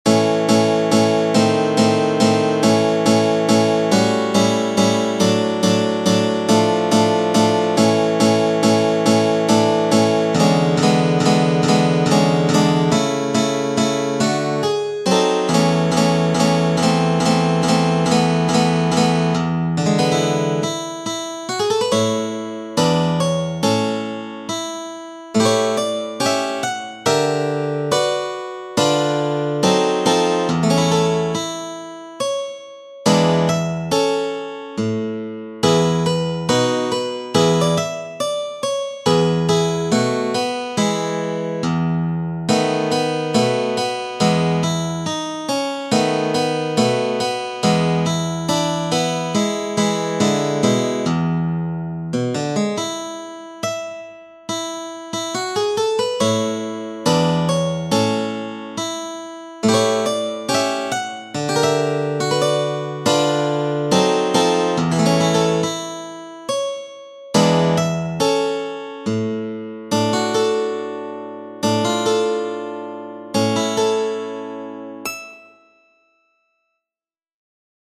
Eventide (sera) – in La***[tab][-♫-]